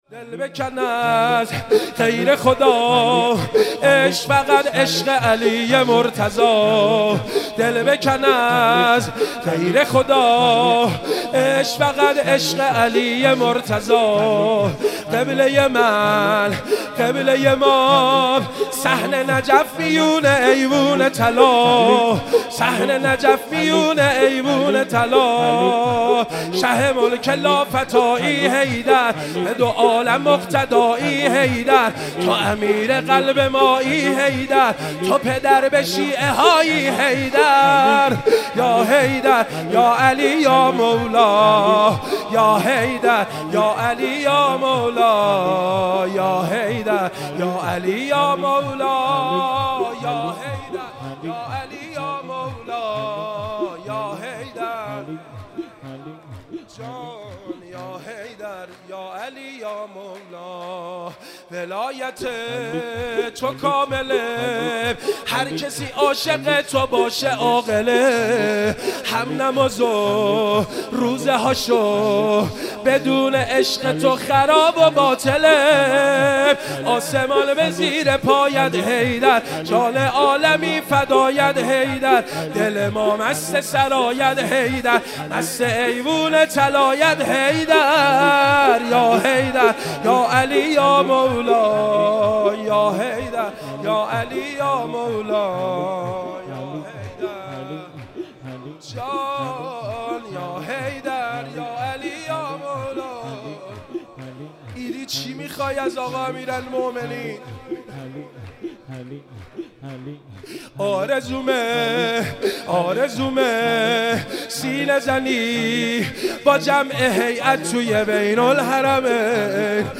شور - دل بکن از غیر خدا - جلسه هفتگی جمعه 25 مرداد 1398